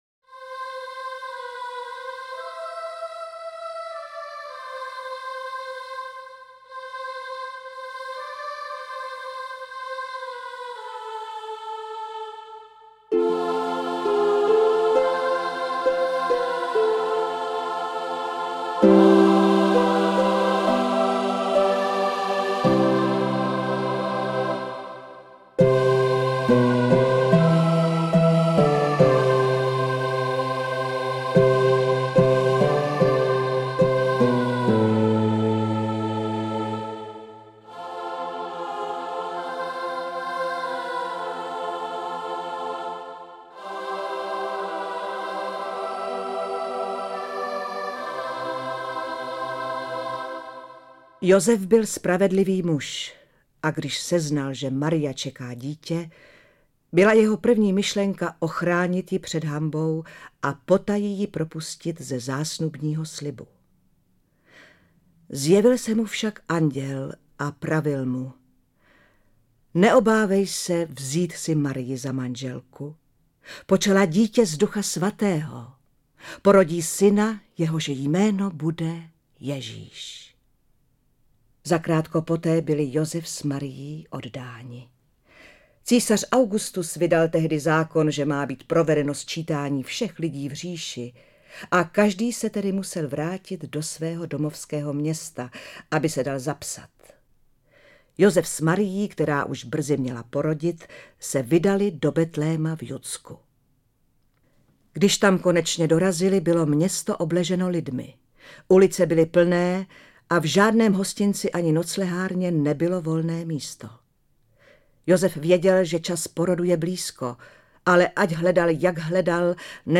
Interpreti:  Marek Eben, Dana Syslová
AudioKniha ke stažení, 35 x mp3, délka 1 hod. 19 min., velikost 70,9 MB, česky